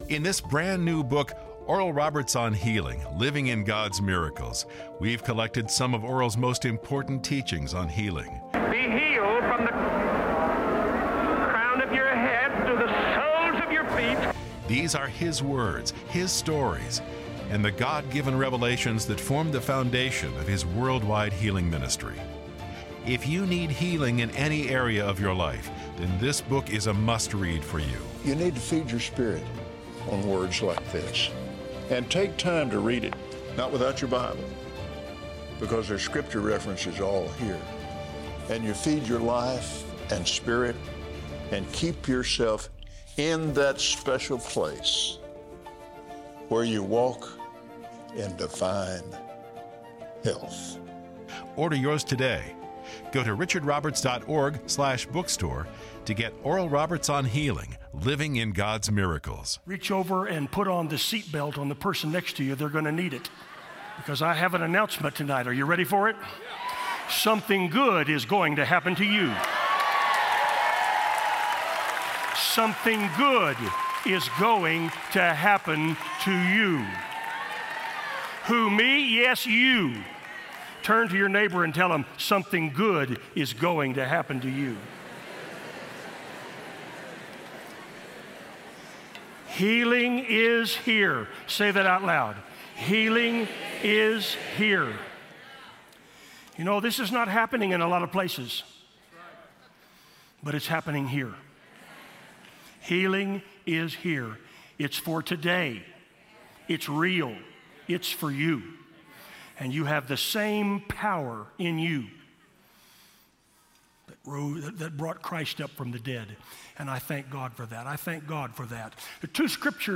As you listen, you will have the opportunity to release your faith for healing during a powerful time of prayer. You can also learn how to pray for others in need of healing.